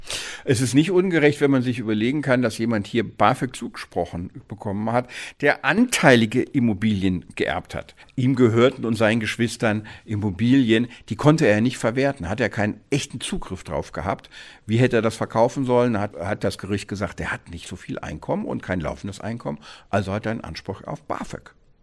O-Ton: Kein Zugriff auf Erbe – Vermögen wird beim Bafög nicht angerechnet – Vorabs Medienproduktion